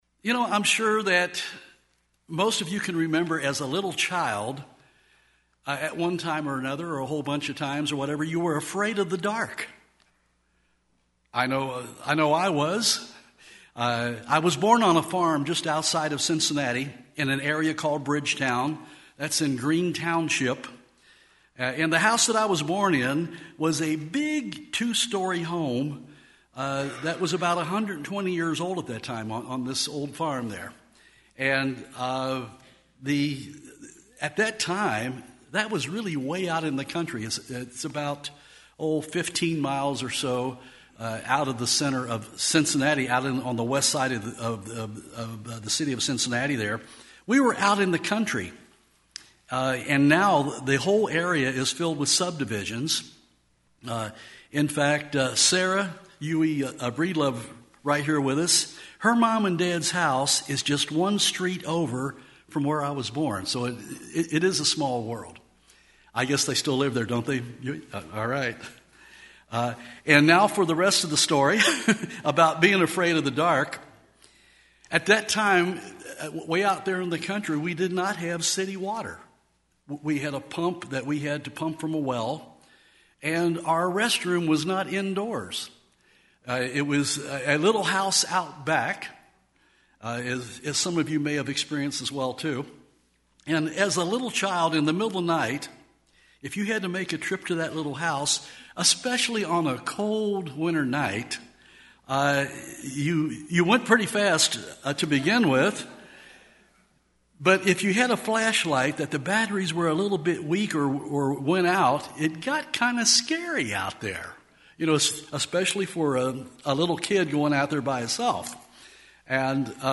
Print Jesus Christ is the light of the world, and as ambassadors of God's Kingdom in this world, we should ask ourselves, Am I allowing God to shine His light through me? light sermon Studying the bible?